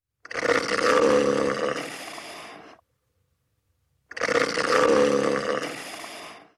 Храп моржа в океане